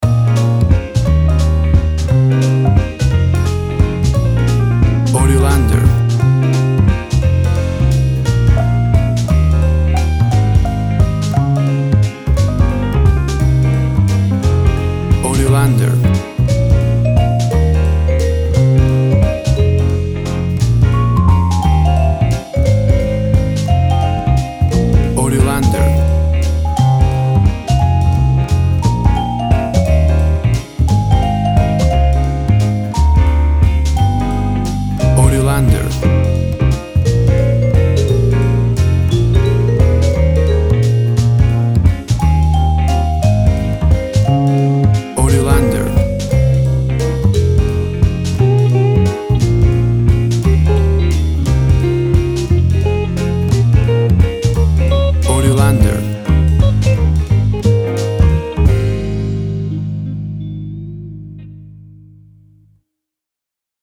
Jazz Waltz music.
Tempo (BPM) 175